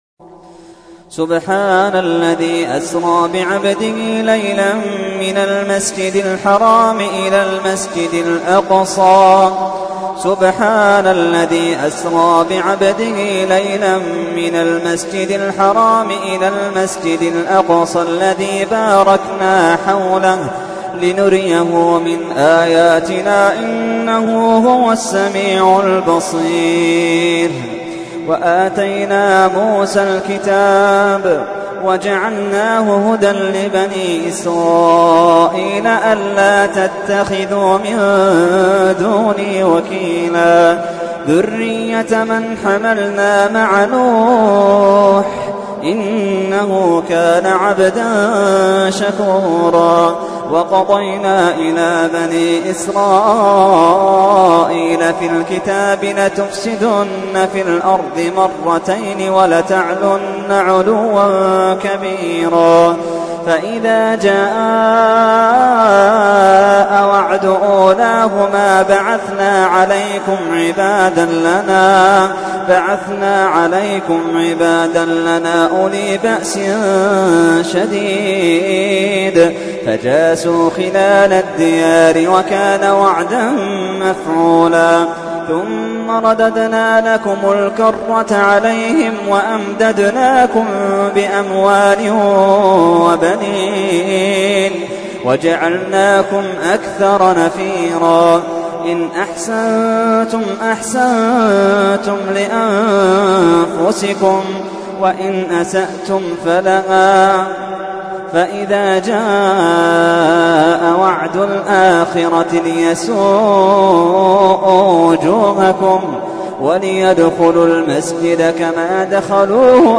تحميل : 17. سورة الإسراء / القارئ محمد اللحيدان / القرآن الكريم / موقع يا حسين